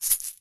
Звуки сада